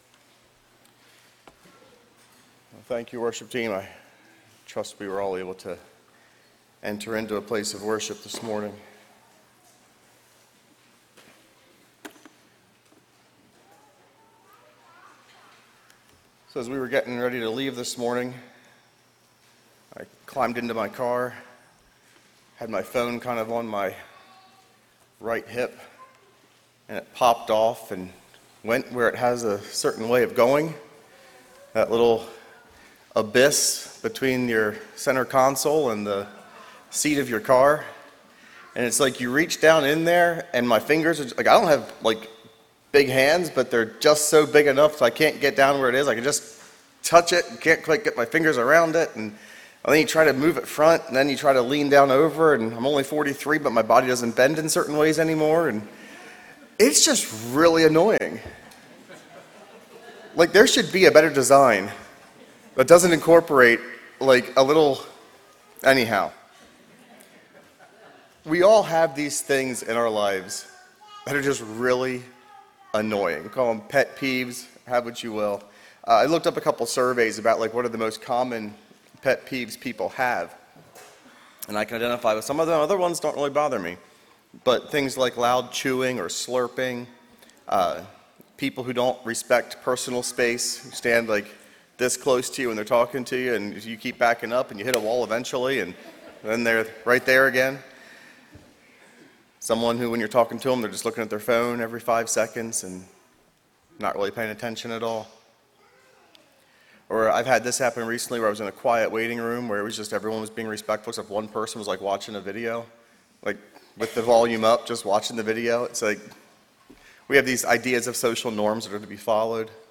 Sermon Archive | - New Covenant Mennonite Fellowship